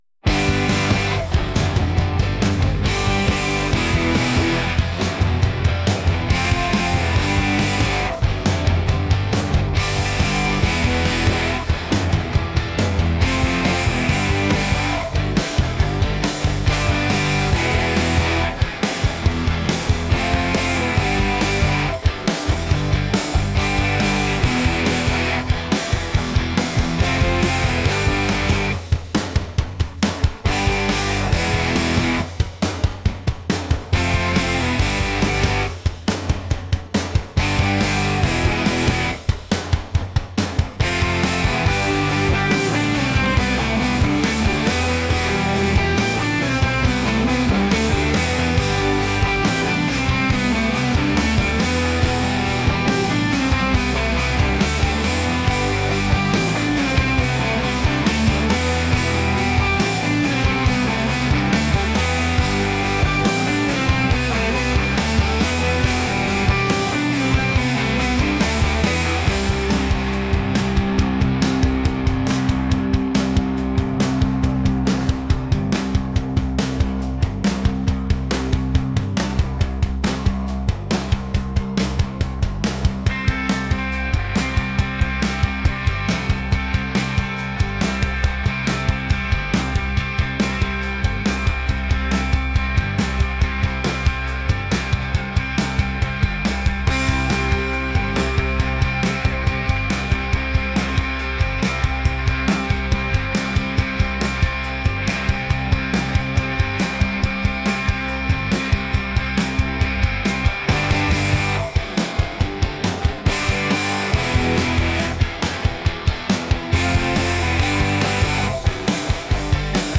energetic | alternative | rock